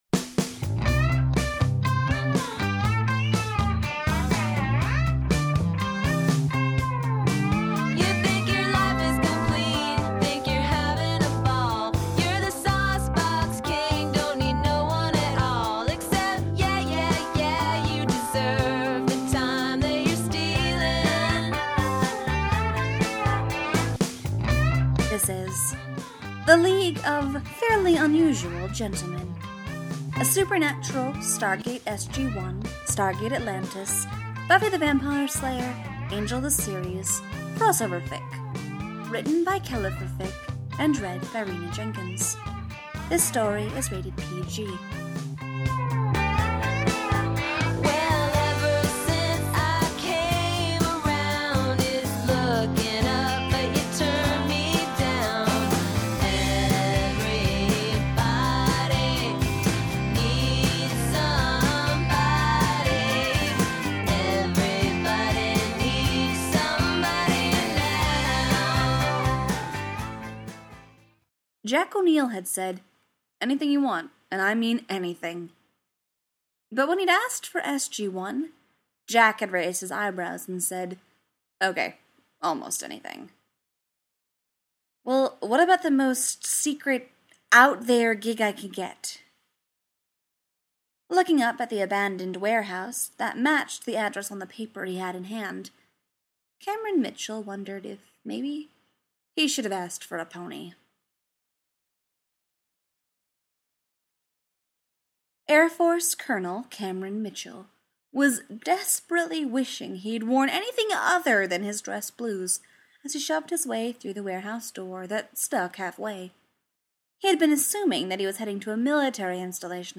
collaboration|two voices